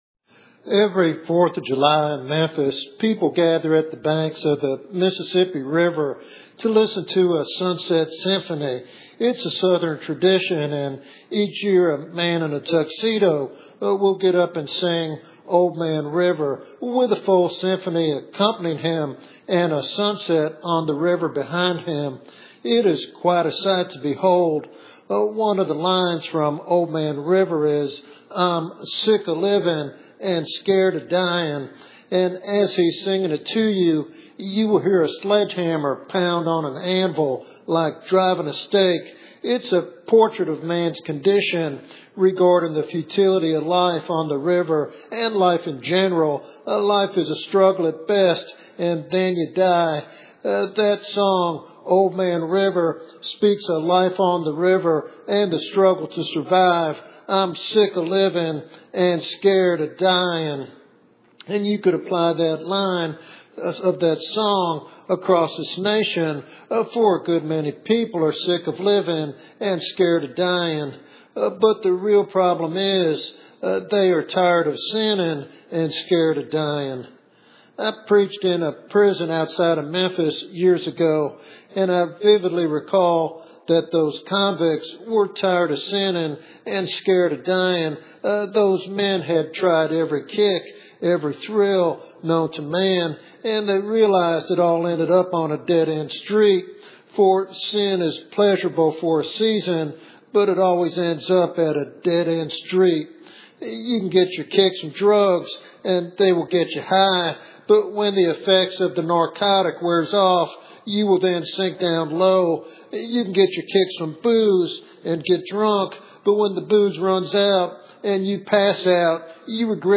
In this compelling evangelistic sermon